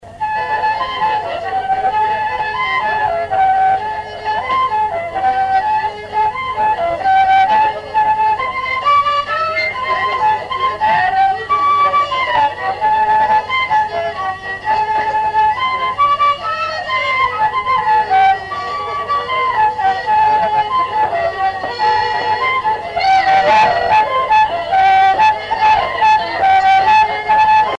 Danse bretonne par La guenille à Pierrot
Saint-Maurice-des-Noues
Répertoire d'un bal folk par de jeunes musiciens locaux
Pièce musicale inédite